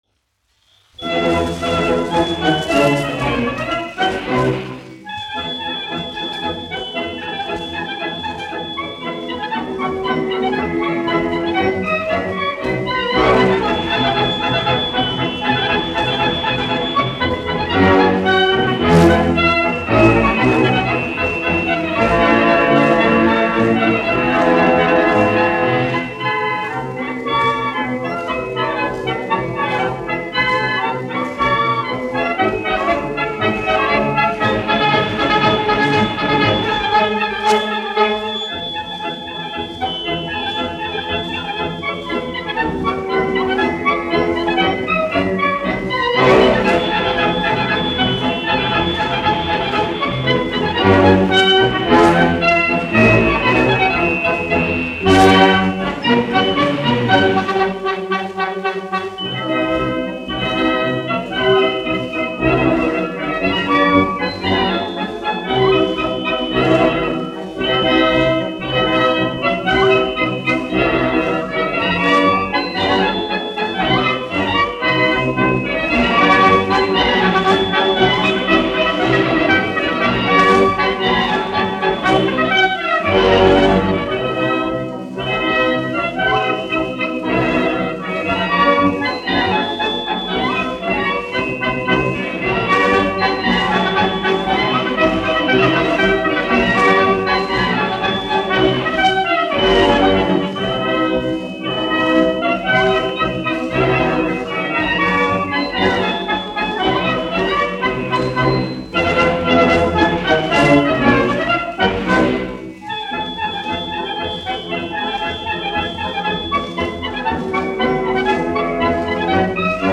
1 skpl. : analogs, 78 apgr/min, mono ; 25 cm
Marši
Pūtēju orķestra mūzika, aranžējumi
Skaņuplate